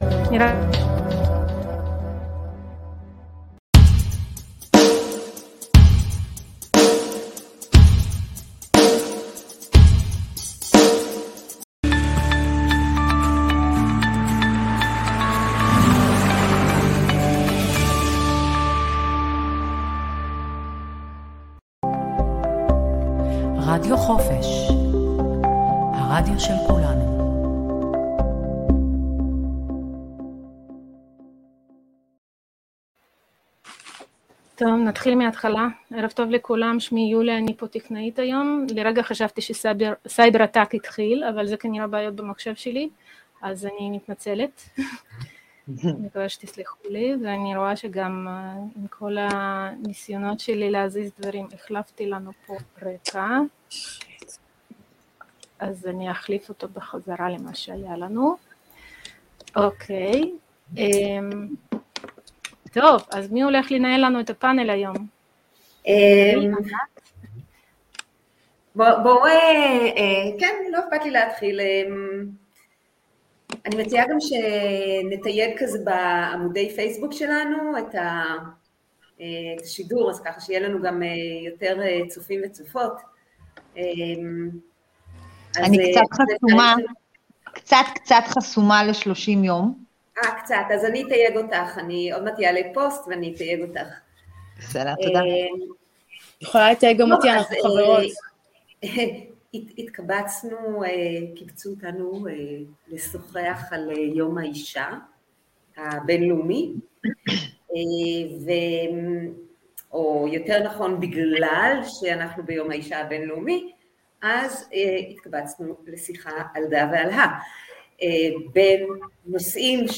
פאנל נשים ליום האישה רדיו חופש